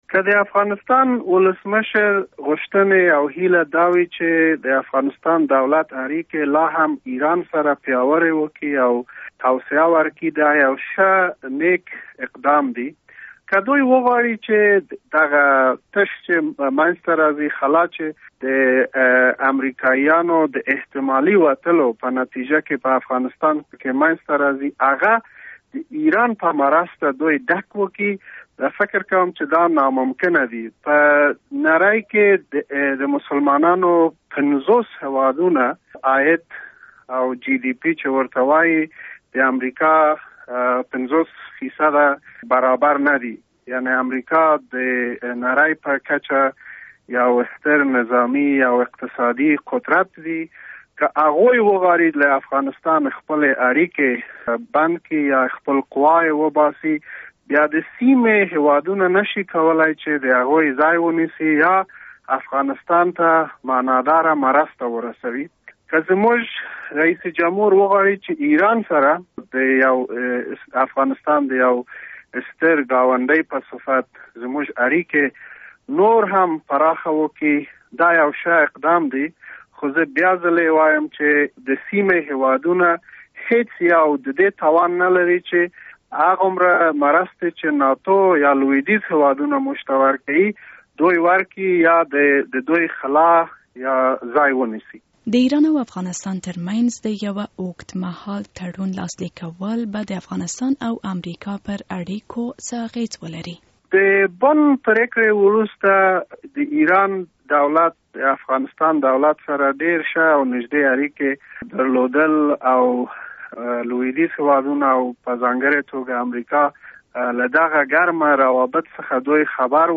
له امرالله صالح سره مرکه